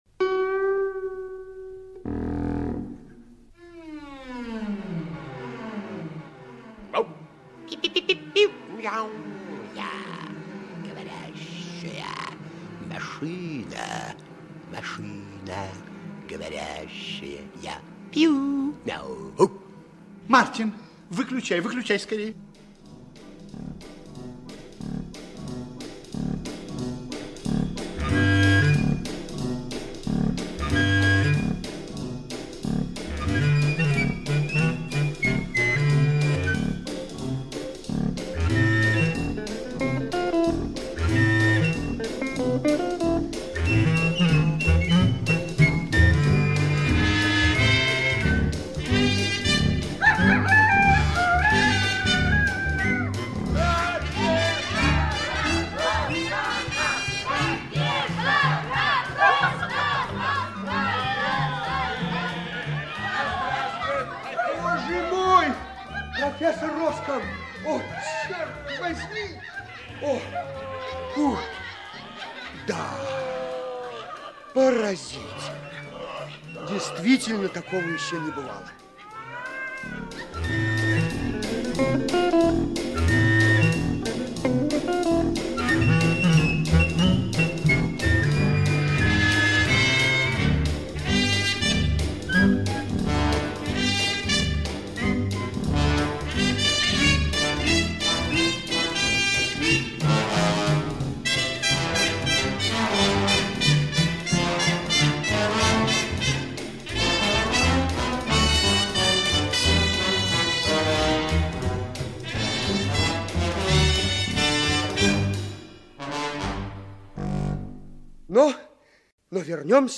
Говорящая машина - аудиосказка Крюсса - слушать онлайн